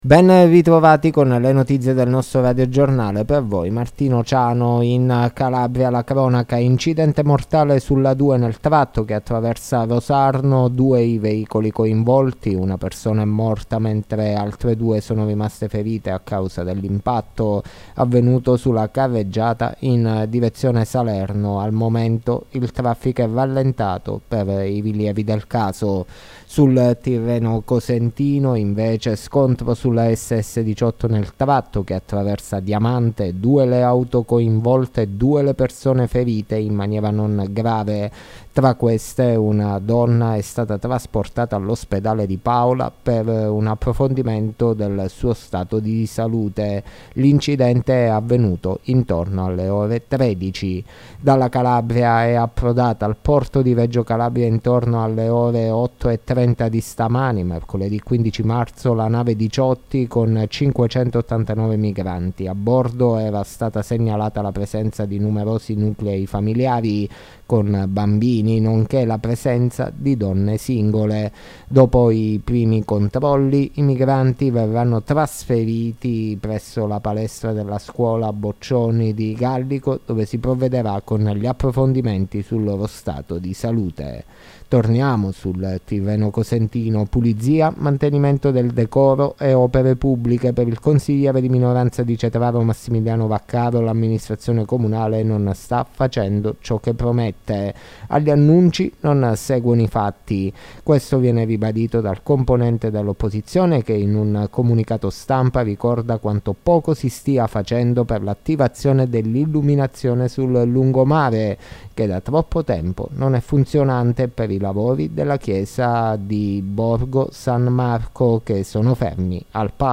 LE NOTIZIE DELLA SERA DI MERCOLEDì 15 MARZO 2023